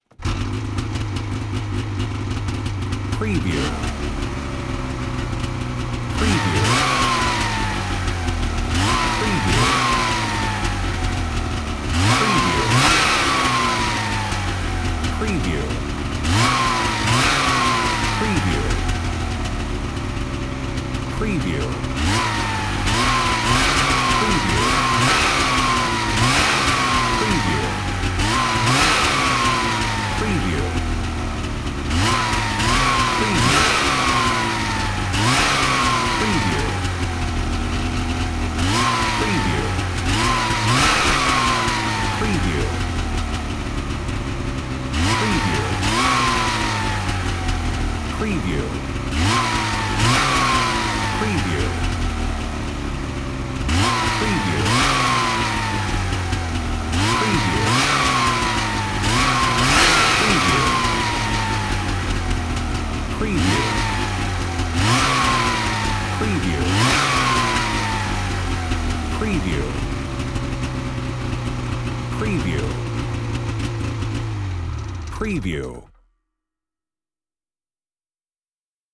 Snowmobile Start, Idle, Rev & Turn Off Wav Sound Effect #1
Description: Snowmobile starts, idles, revs and turns off
Properties: 48.000 kHz 24-bit Stereo
Keywords: snowmobile, snow mobile, skidoo, ski-doo, ski doo, winter, engine, start, idle, idling, rev, revs, revving, turn, off
snowmobile-start-idle-rev-and-turn-off-preview-01.mp3